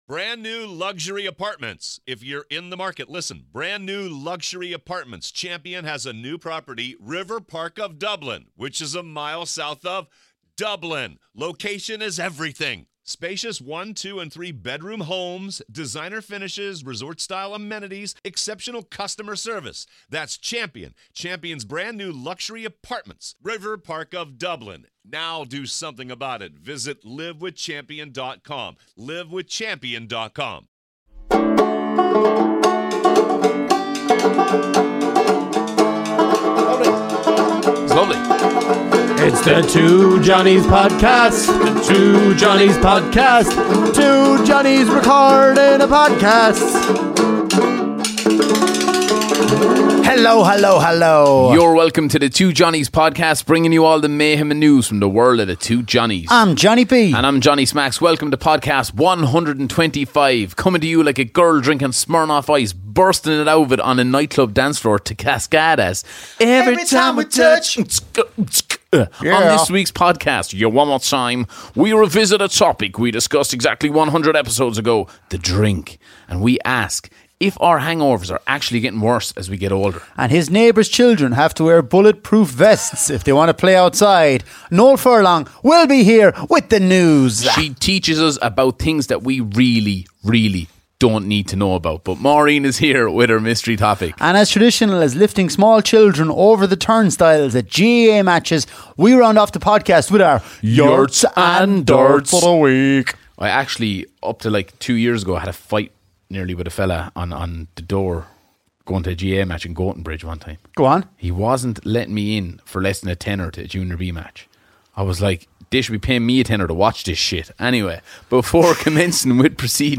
Ireland's favourite comedy duo tackle the Big issues, this week: